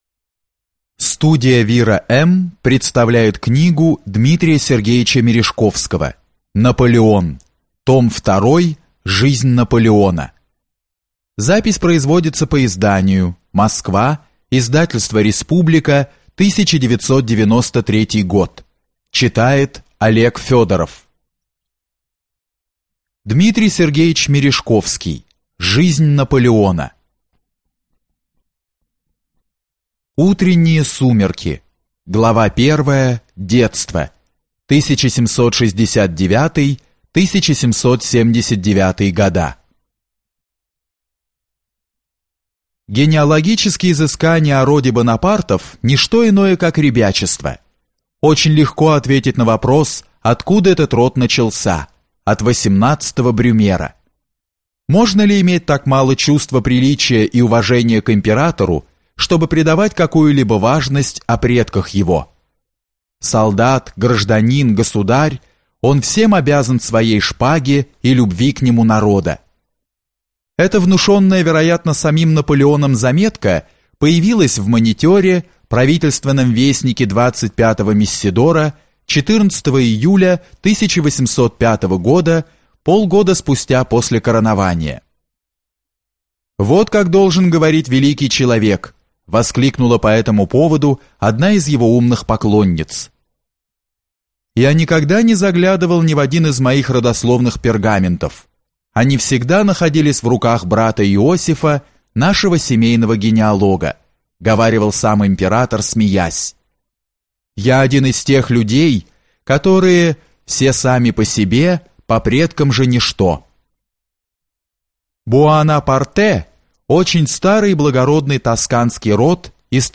Аудиокнига Жизнь Наполеона | Библиотека аудиокниг